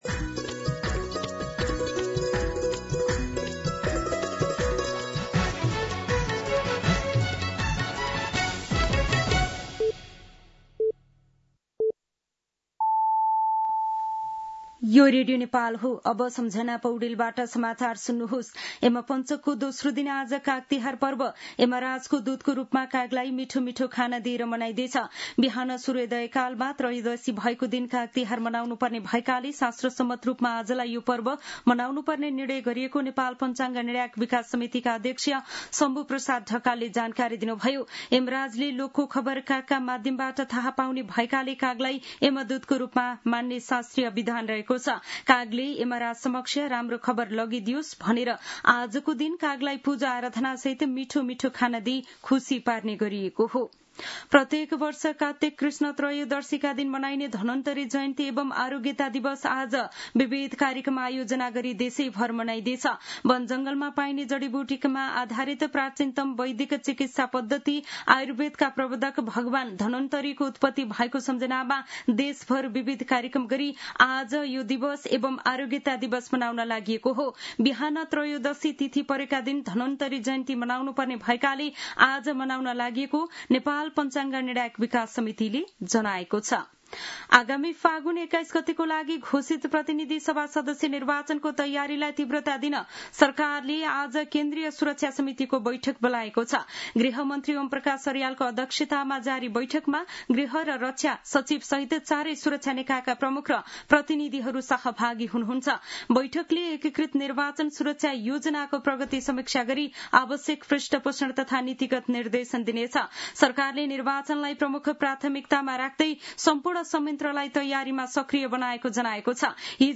मध्यान्ह १२ बजेको नेपाली समाचार : १८ पुष , २०२६
12-pm-Nepali-News-7.mp3